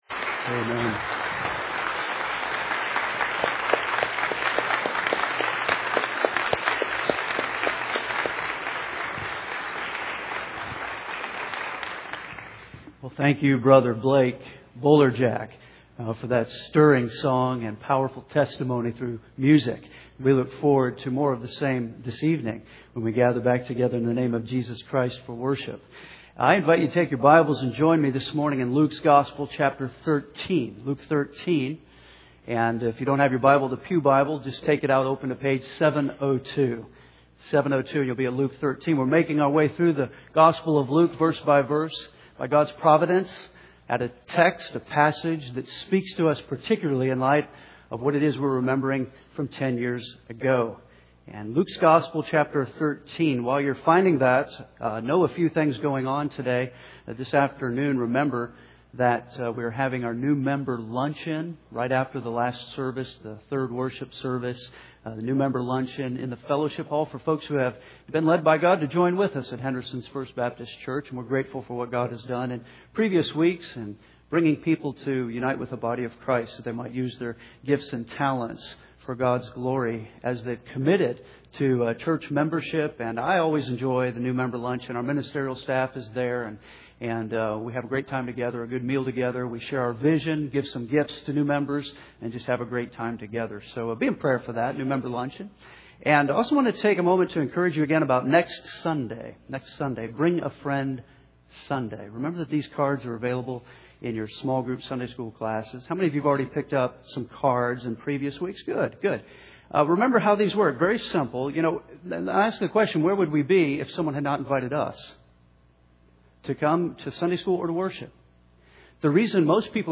Henderson’s First Baptist Church, Henderson